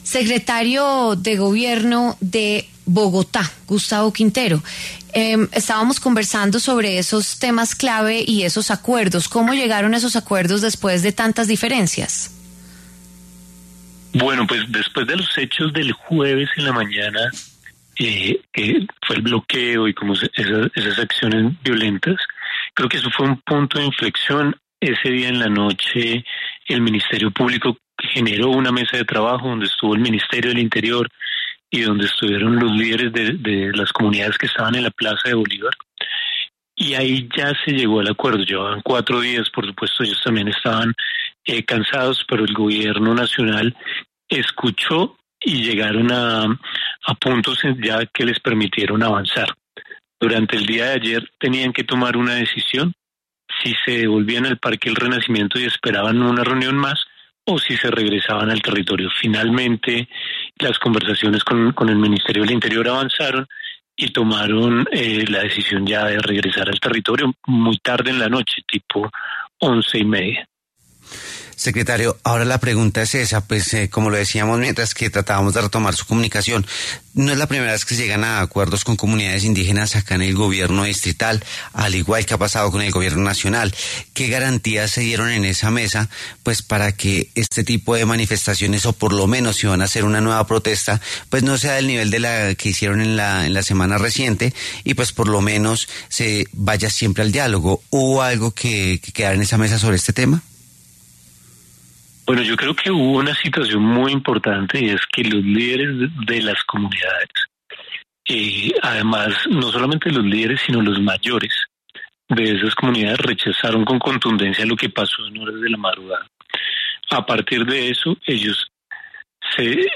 Gustavo Quintero, secretario de Gobierno de Bogotá, habló en los micrófonos de W Fin de Semana sobre los acuerdos alcanzados con los indígenas en la capital del país y las medidas que se tomarán para que regresen a sus regiones.